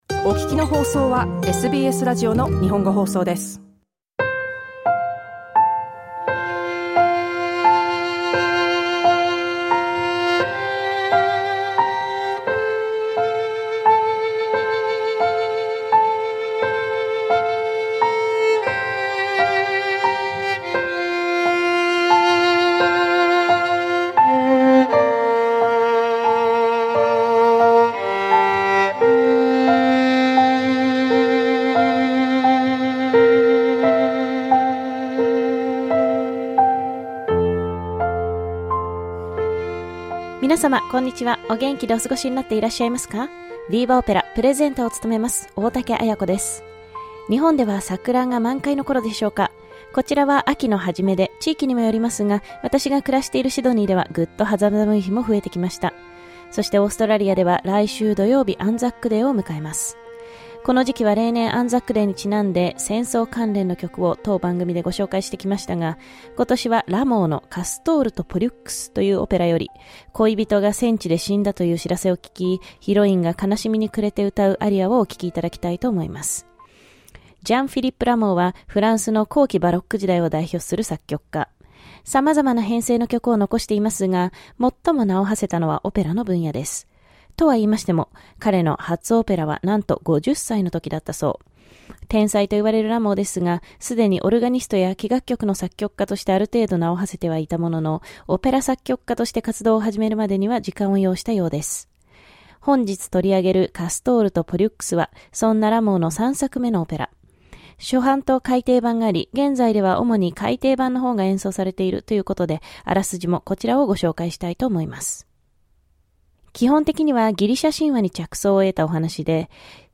A poignant and beautiful aria that expresses the feelings of a heroine who has just lost her beloved on the battlefield.